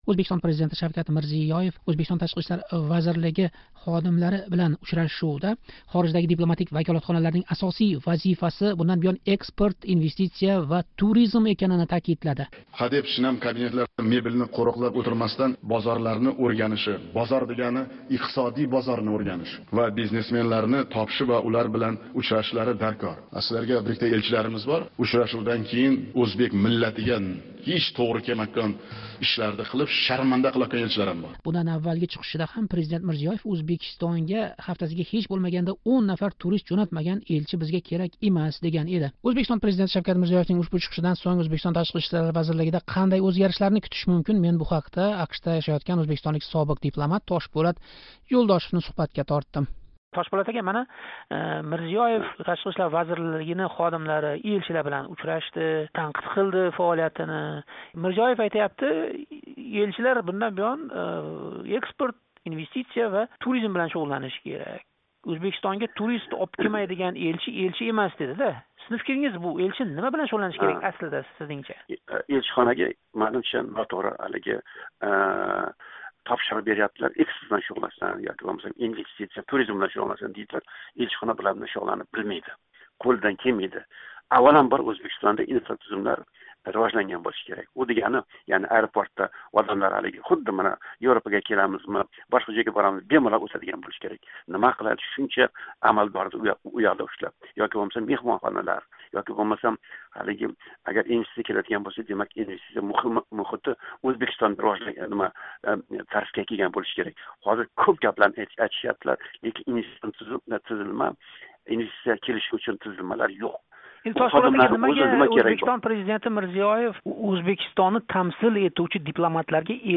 суҳбат.